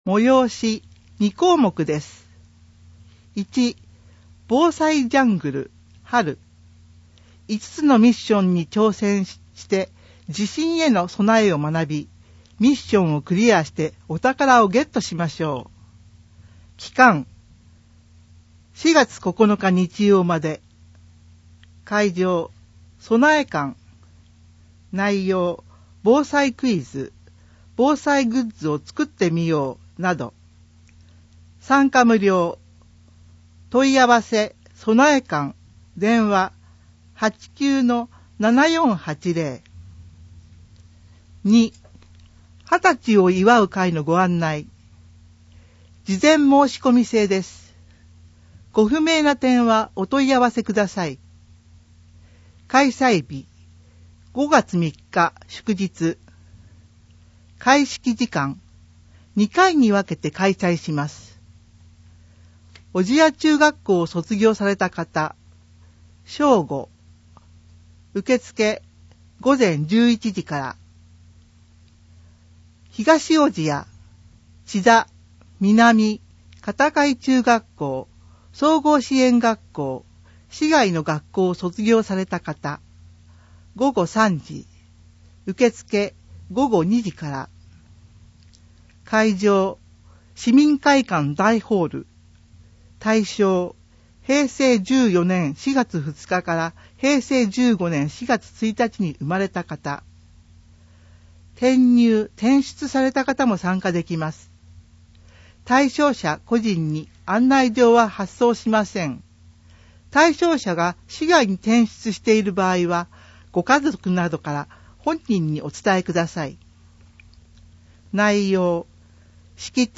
令和5年度広報おぢやの音声訳（MP3） - 小千谷市ホームページ
現在、広報おぢや、社協だより、小千谷新聞の音声訳を行い、希望する方へ無料で音声訳CDをお届けしています。